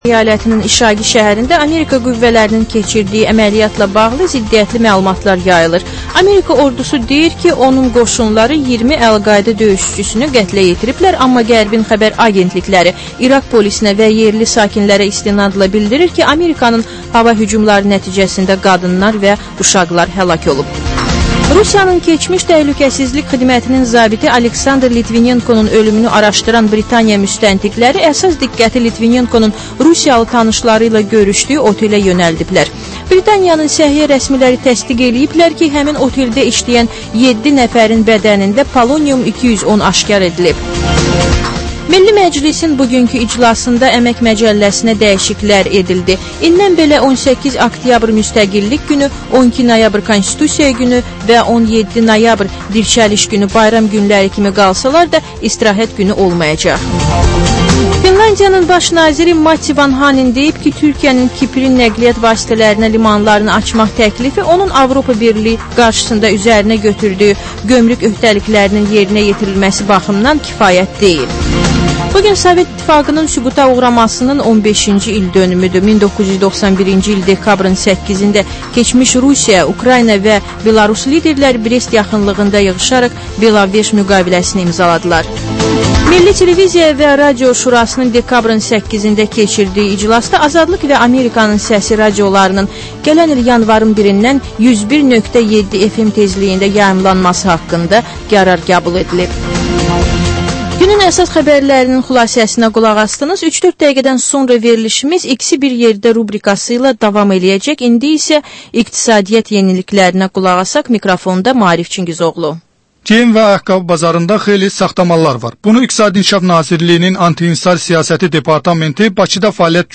Həftənin aktual məsələsi barədə dəyirmi masa müzakirəsi